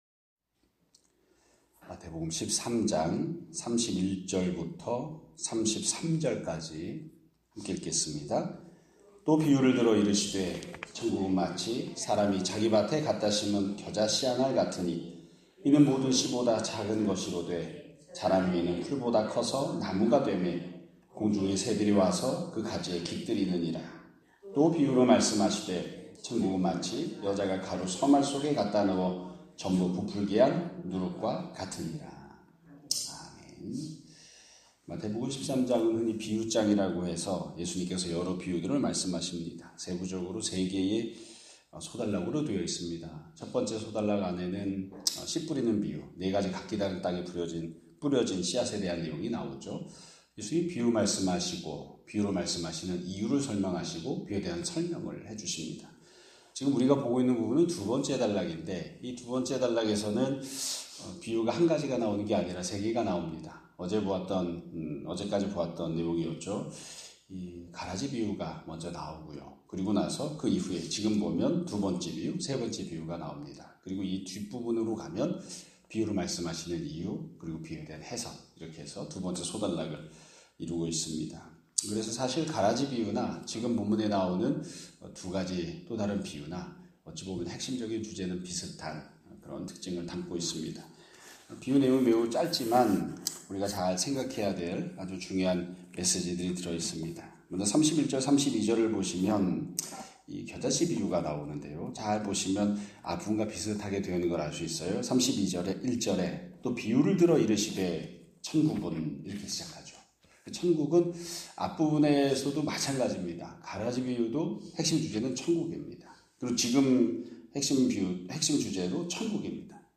2025년 9월 30일 (화요일) <아침예배> 설교입니다.